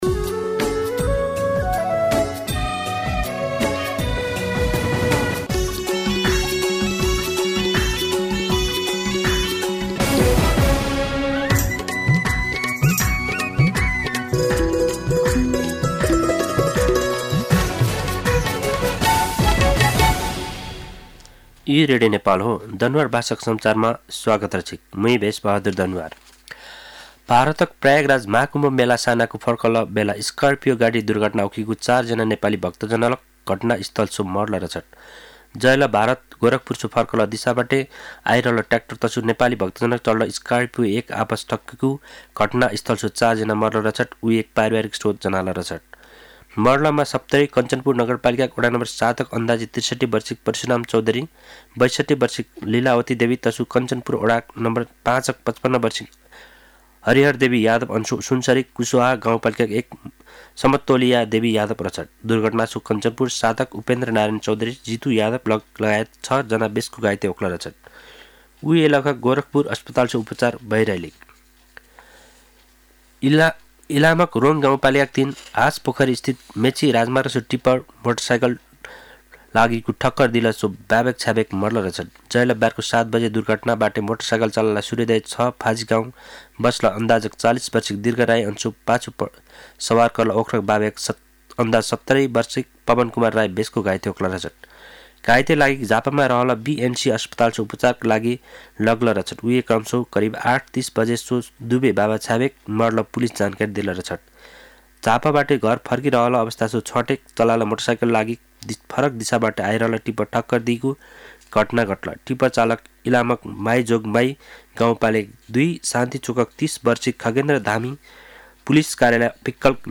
दनुवार भाषामा समाचार : ११ फागुन , २०८१
danuwar-news-5.mp3